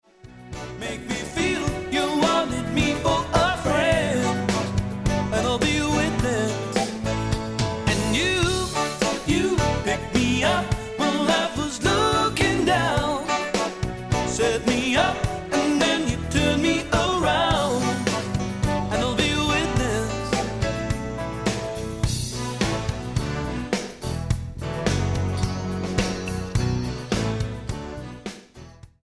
STYLE: Pop